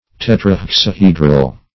Search Result for " tetrahexahedral" : The Collaborative International Dictionary of English v.0.48: Tetrahexahedral \Tet`ra*hex`a*he"dral\, a. (Crystallog.)
tetrahexahedral.mp3